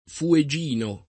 vai all'elenco alfabetico delle voci ingrandisci il carattere 100% rimpicciolisci il carattere stampa invia tramite posta elettronica codividi su Facebook fuegino [ fue J& no ] (meno com. fuegiano [ fue J# no ]) etn.